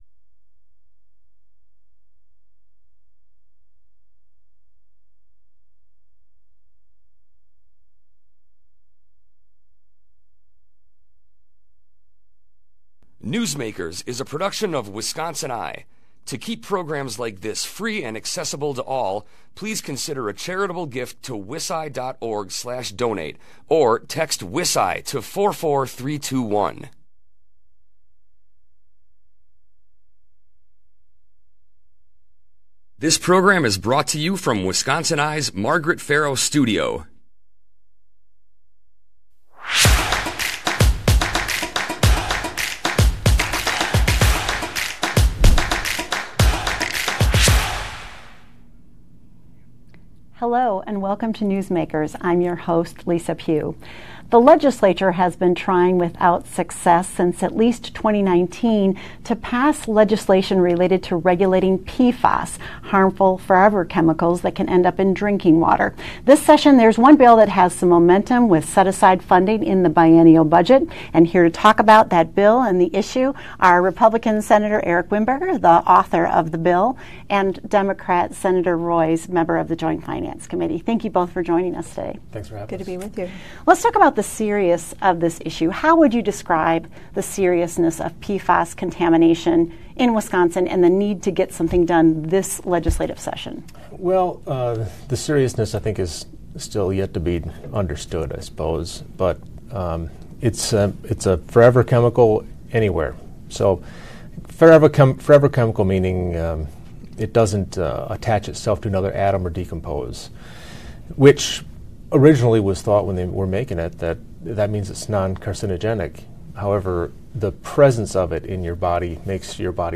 On June 27, 2023, bill author Senator Eric Wimberger (R-Green Bay) and Joint Committee on Finance member, Senator Kelda Roys (D-Madison), joined us on WisconsinEye’s Newsmakers with a status update on the legislation.